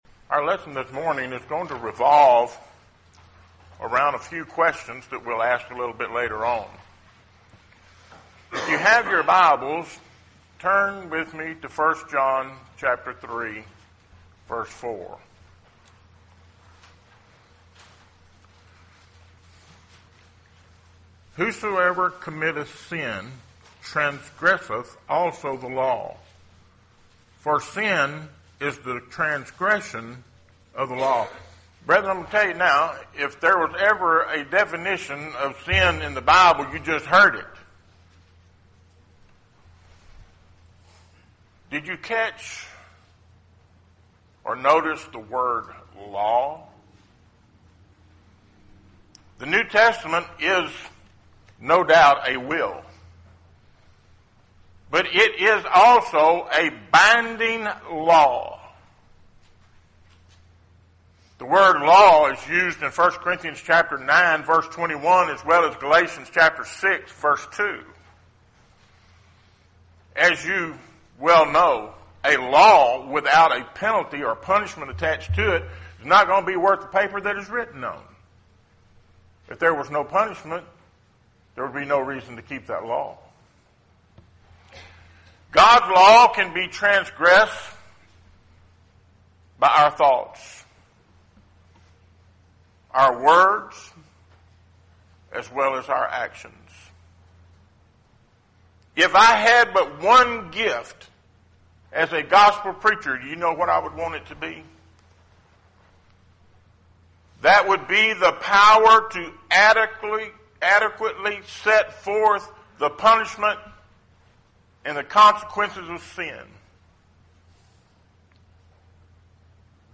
Series: Sermon